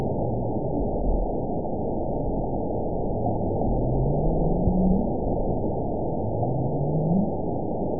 event 916153 date 12/26/22 time 05:20:04 GMT (2 years, 5 months ago) score 8.56 location INACTIVE detected by nrw target species NRW annotations +NRW Spectrogram: Frequency (kHz) vs. Time (s) audio not available .wav